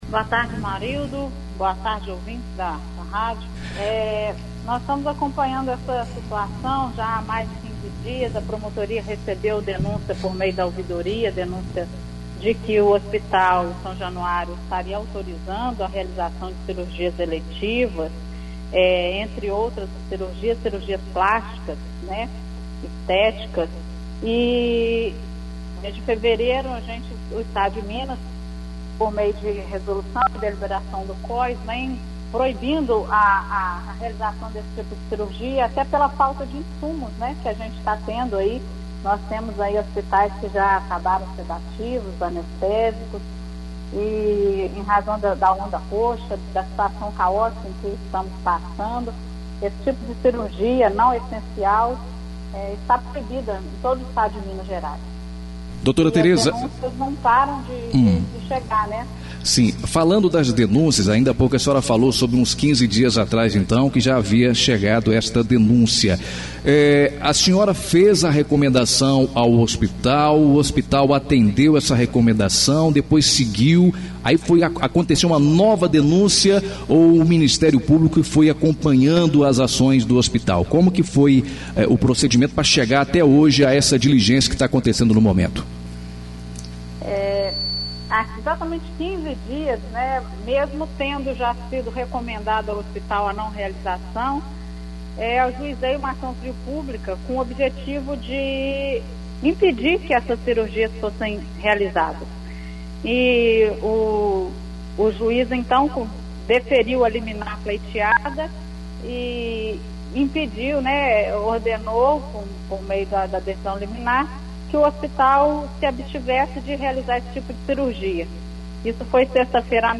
Entrevista exibida na Rádio Educadora AM/FM Ubá-MG